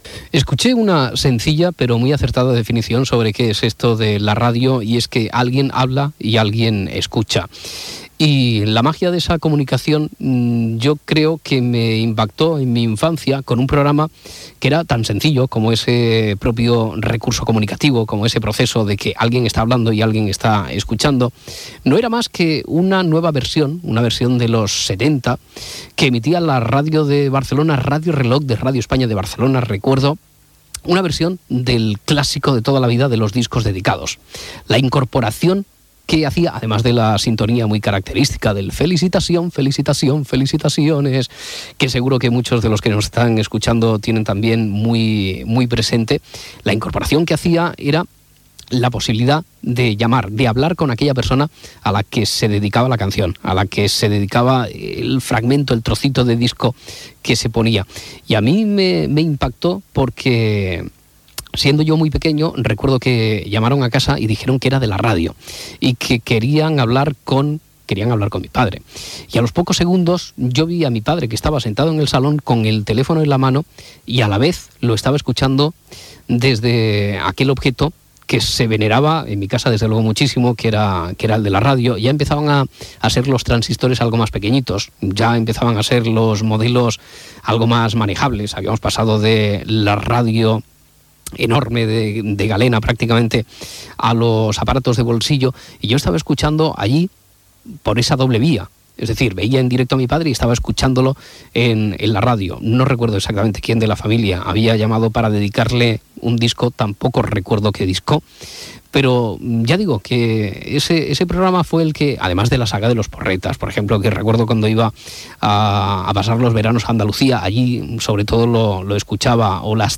S'inclou un fragment de "La saga de los Porretas". Indicatiu del programa.
Divulgació